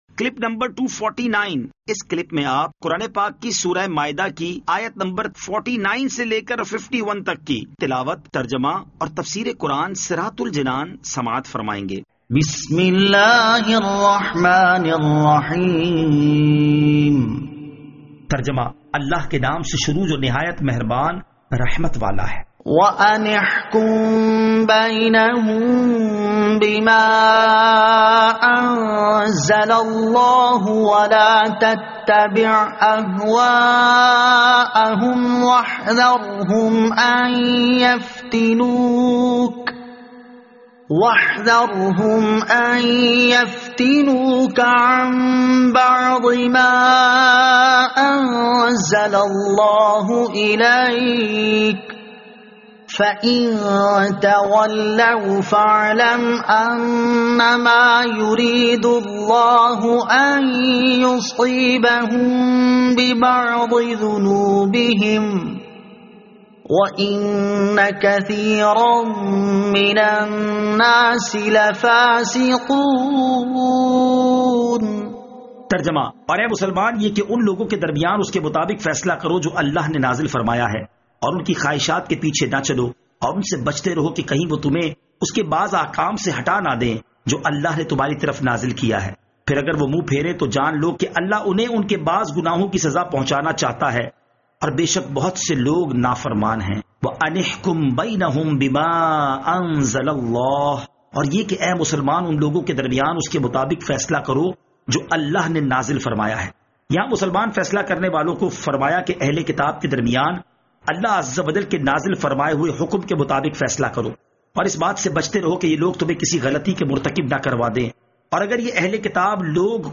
Surah Al-Maidah Ayat 49 To 51 Tilawat , Tarjama , Tafseer